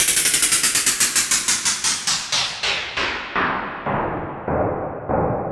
Tape Stop Sfx
tape-stop-sfx.wav